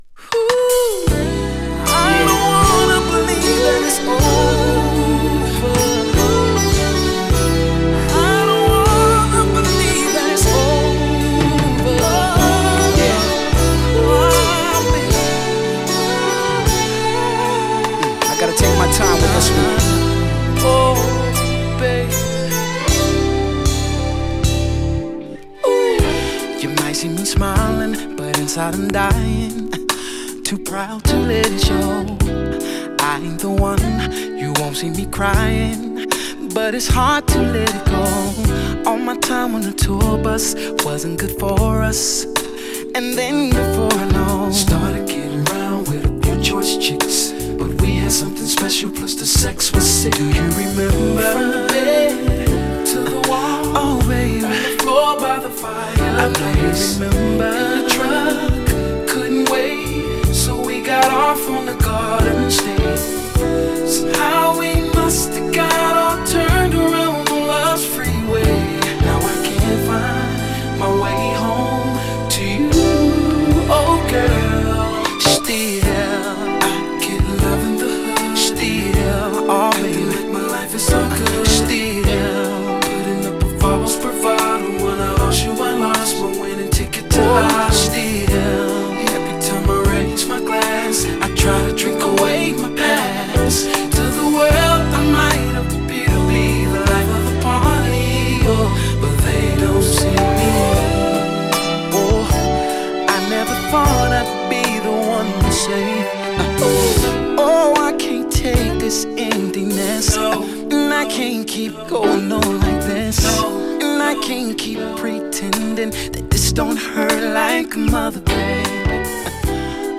2. > R&B